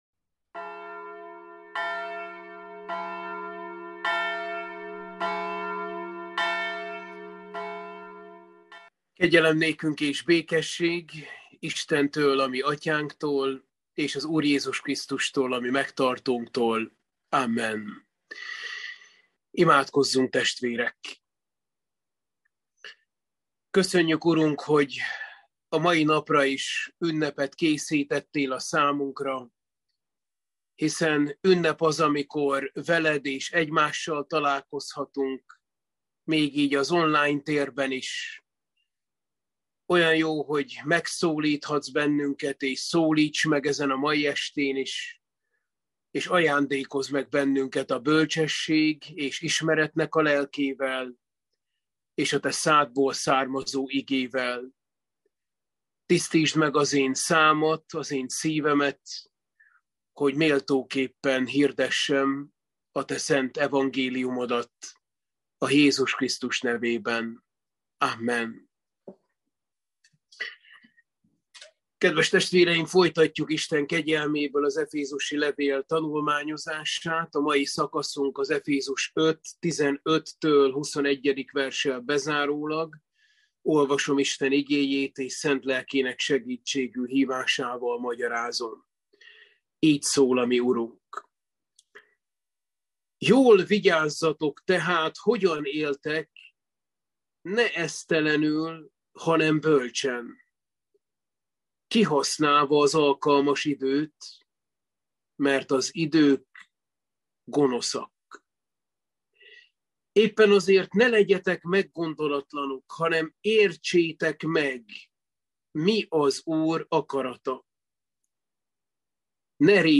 Efézusi levél – Bibliaóra 21